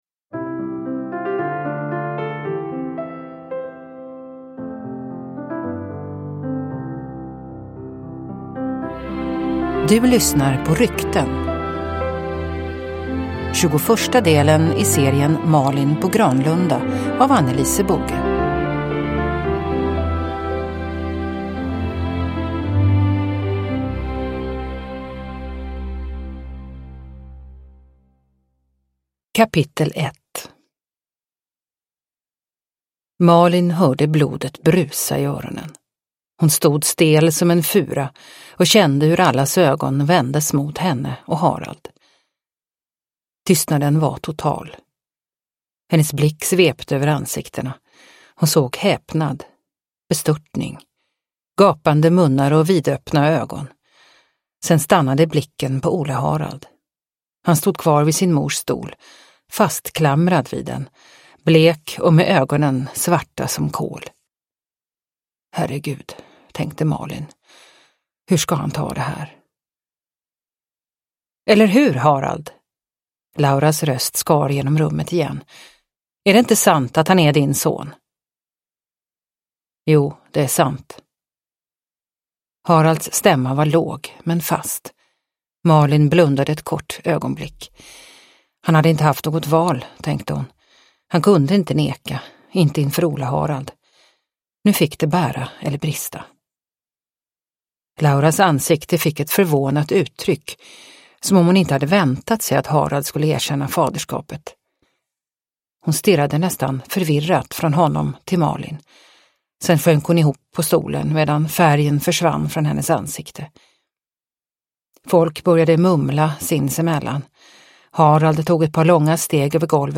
Rykten (ljudbok) av Anne-Lise Boge